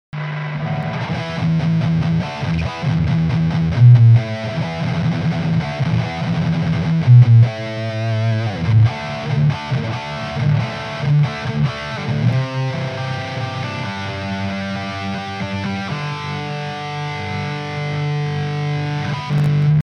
Gitara UPG, snimac KA PAF. V kazdom preste som vypol reverb, nech je menej pukancov, mam slaby pocitac, nie?
Sumu je tam neurekom, aj preto hravam cca na 1/3 gaine ako si mal nastaveny ty.
Mp3 su stereo, pre tvoje pohodlie.
okrem toho ti to este pulzuje hlasitostne - tvrdo to limutuje, co znamena ze mas prevaleny este aj output